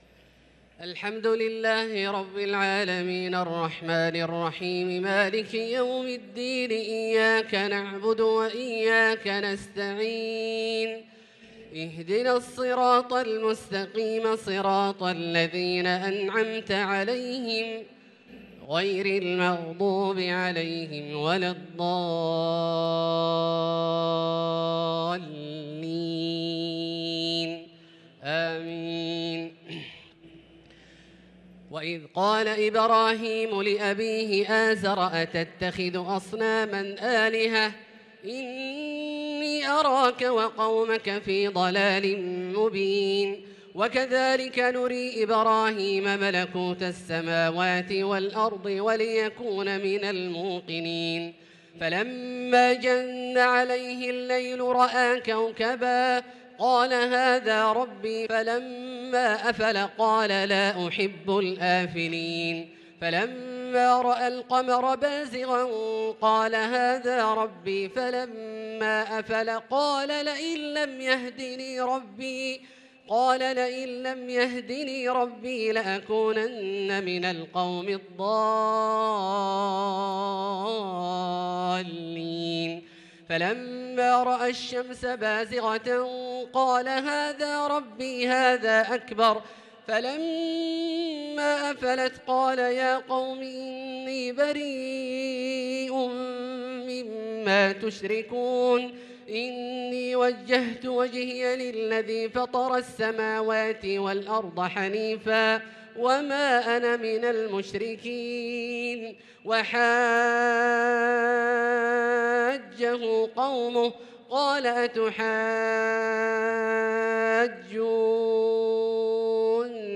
صلاة التراويح ليلة 10 رمضان 1443 للقارئ عبدالله الجهني - الأربع التسليمات الأولى صلاة التراويح